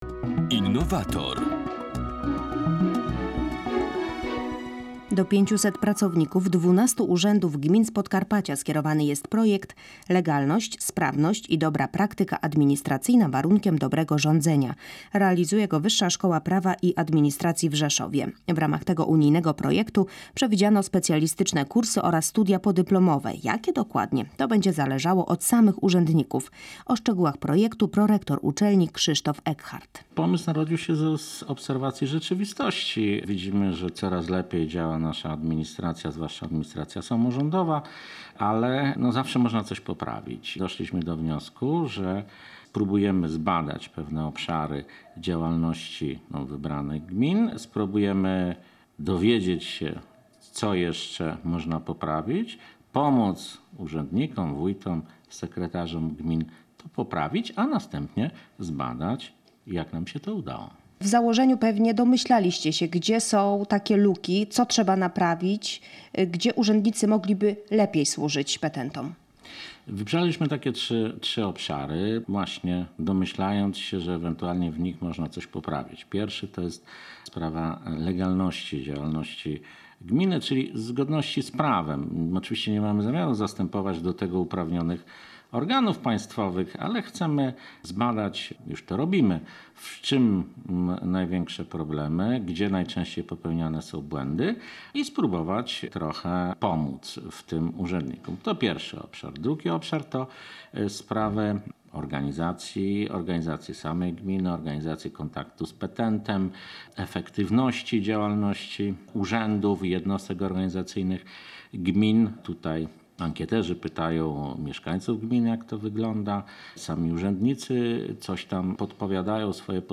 Program wyemitowany został 19.09.2012 na antenie Polskiego Radia Rzeszów.